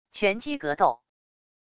拳击格斗wav音频